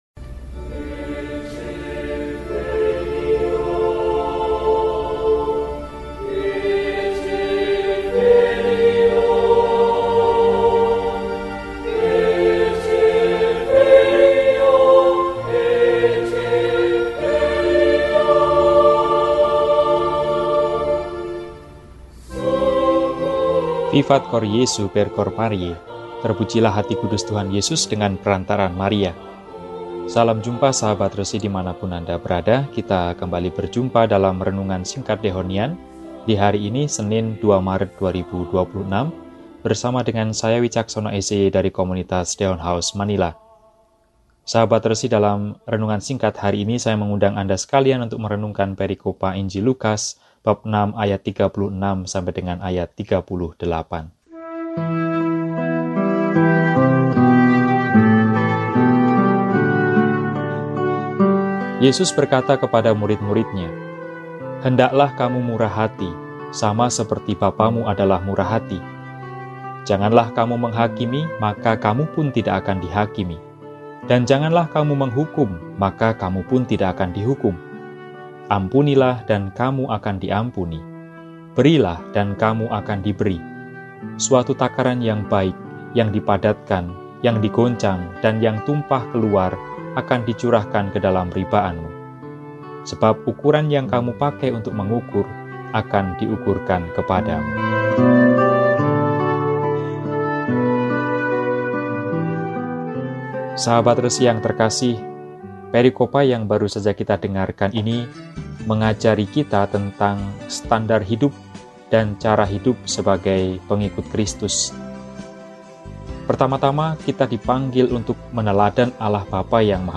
Senin, 02 Maret 2026 – Hari Biasa Pekan II Prapaskah – RESI (Renungan Singkat) DEHONIAN